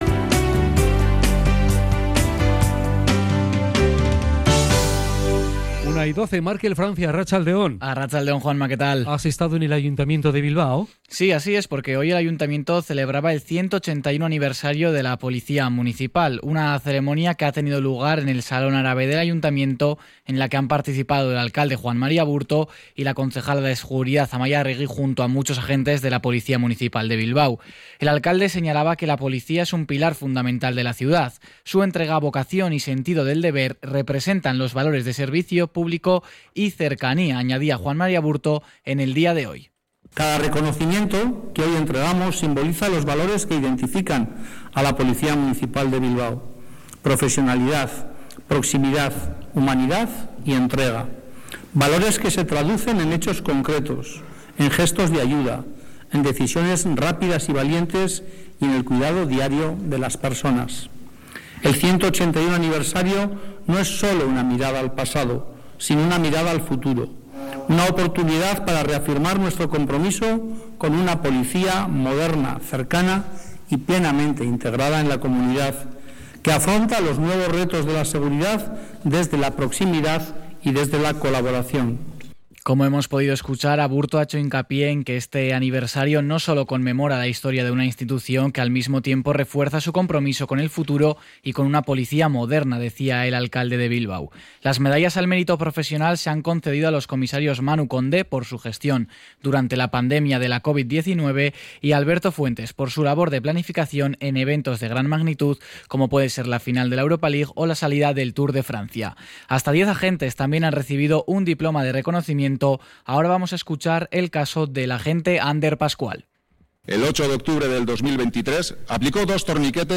cRONICA-181-MUNICIPALES.mp3